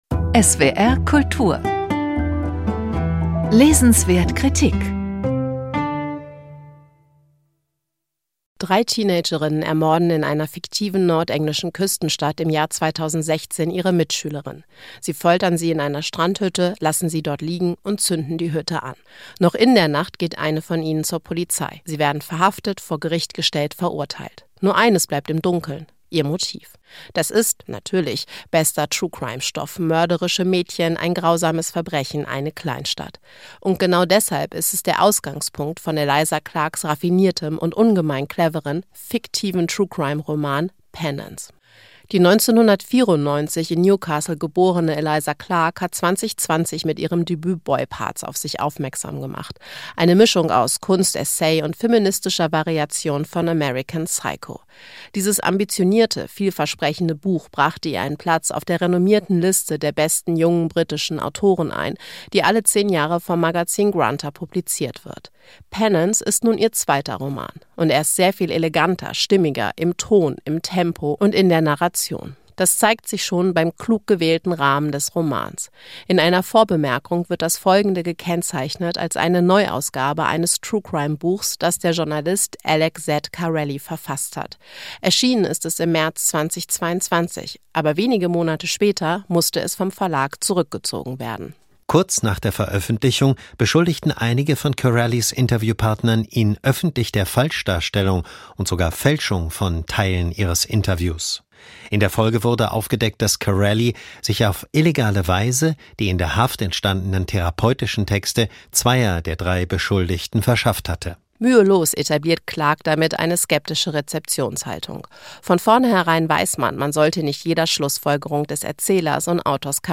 Rezension